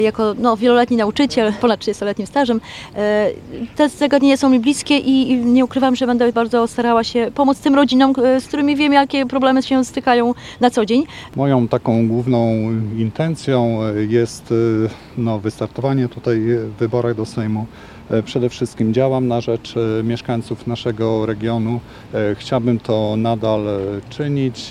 Rozpoczęcie kampanii wyborczej na Suwalszczyźnie ogłosili w środę (21.08.19) w Suwałkach kandydaci Prawa i Sprawiedliwości do Sejmu. Podczas zorganizowanej w tym celu konferencji prasowej wystąpili Elżbieta Puczyłowska, radna powiatu augustowskiego, która startuje z 17 miejsca listy i Marek Dziatkowski, przewodniczący Rady Powiatu Suwalskiego, kandydujący z 23 miejsca.